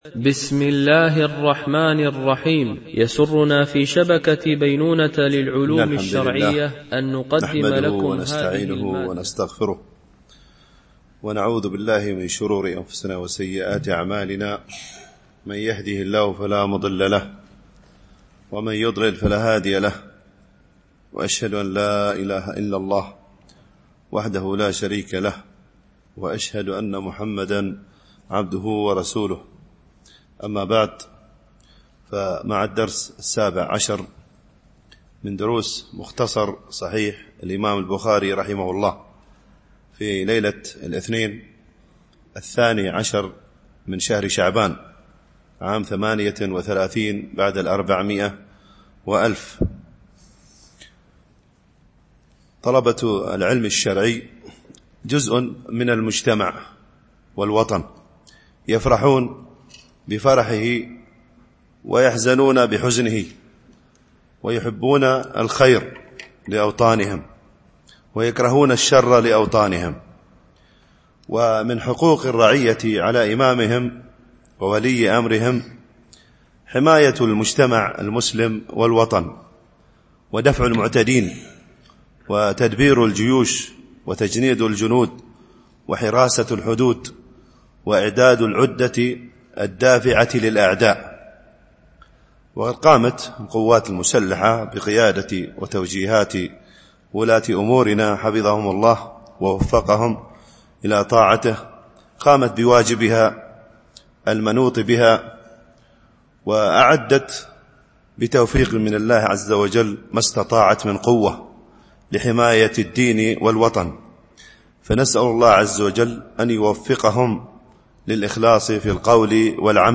شرح مختصر صحيح البخاري ـ الدرس 17 (الحديث 38 - 40)